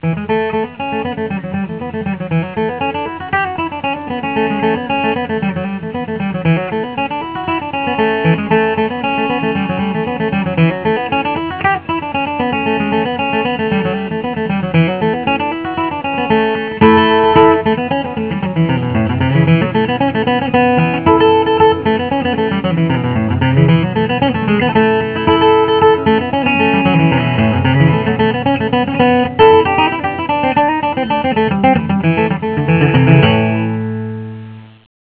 I recorded the songs right at my desk, on my Macintosh.